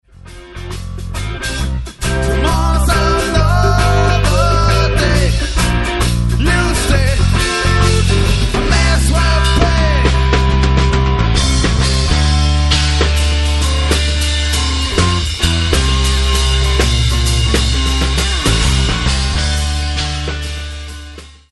Musik zum Tanzen, Mitsingen und Spaß haben.
Vocals, Bass
Guitar
Drums